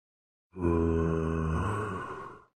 Zombie Minecraft
zombie-minecraft.mp3